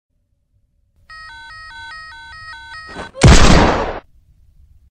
Aa Gun